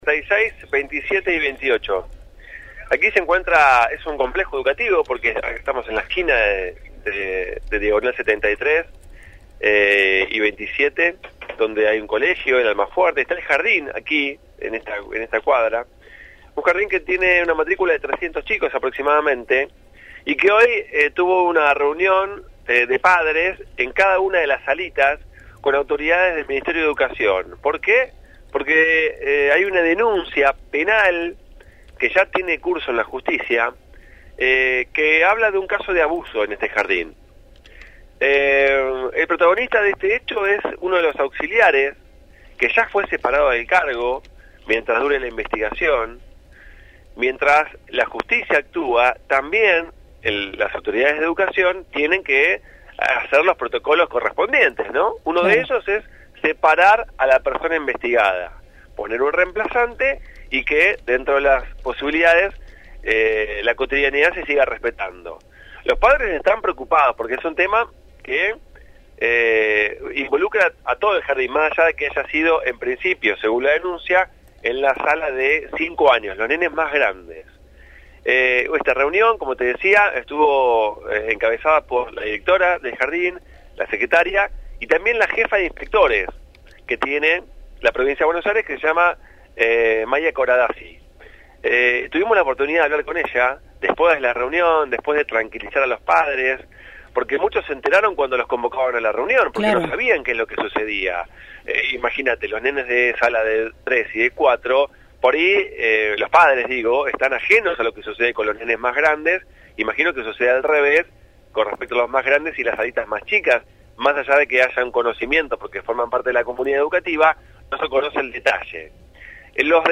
MÓVIL/ Presunto abuso en un jardín de infantes